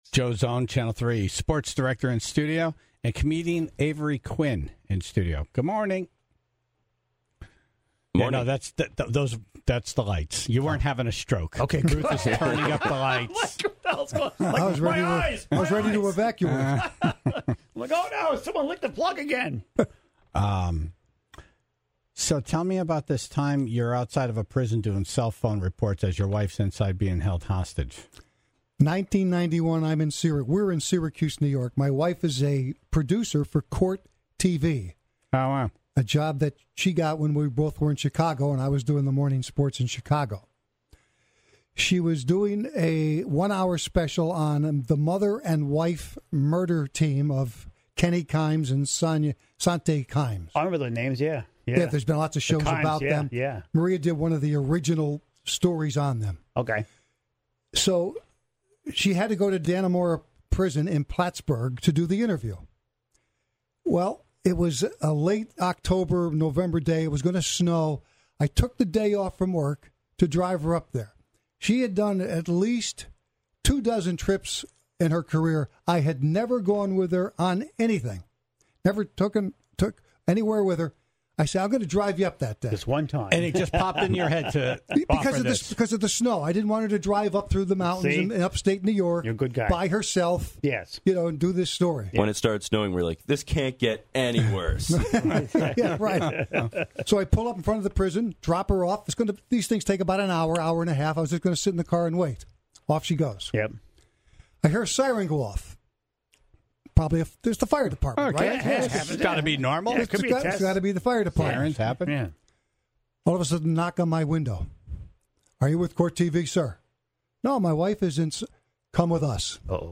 was in studio